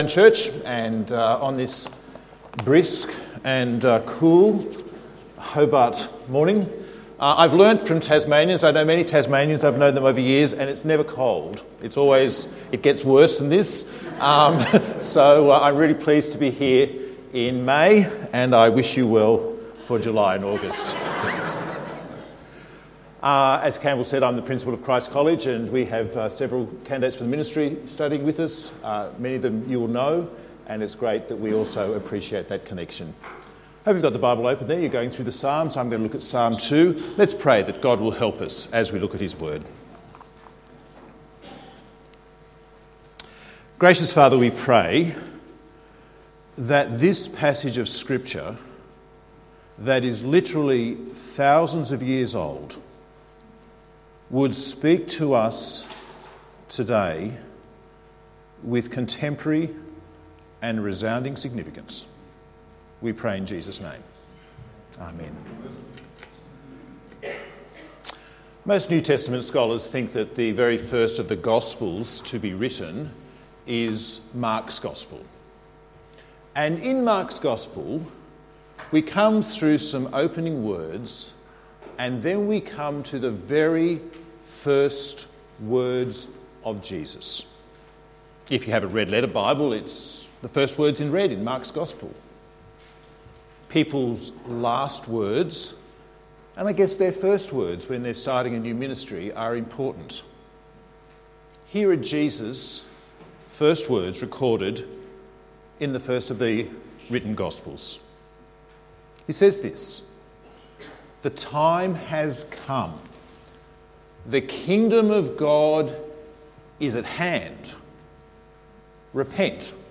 Psalm 2:1-12 Sermon